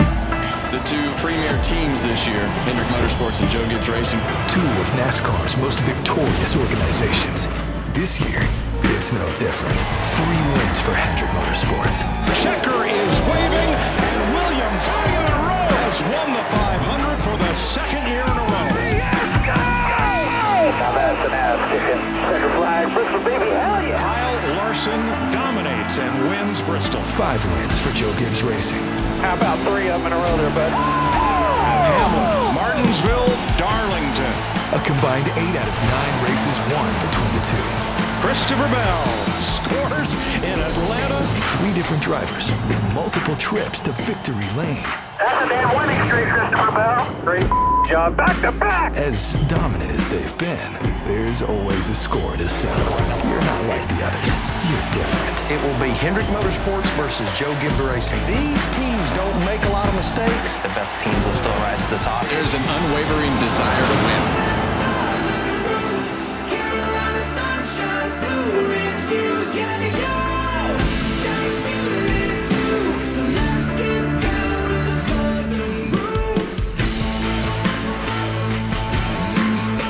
Narration Download This Spot